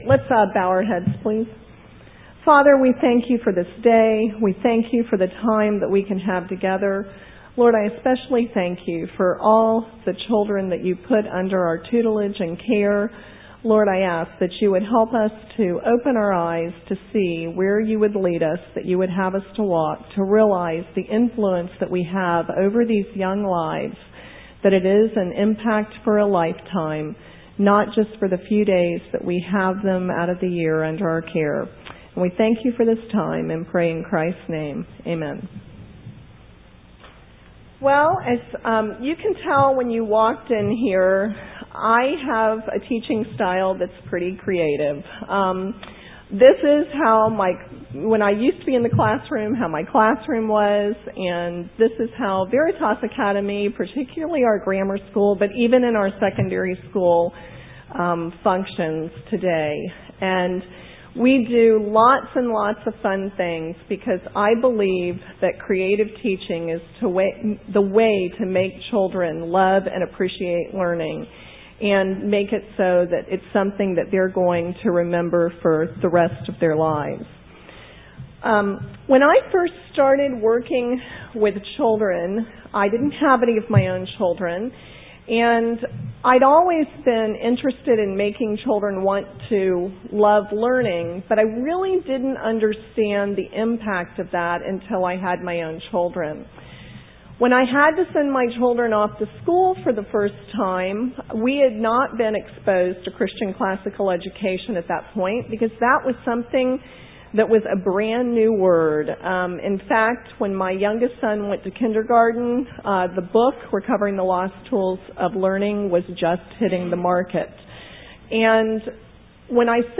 2005 Workshop Talk | 0:49:41 | All Grade Levels, Teacher & Classroom, General Classroom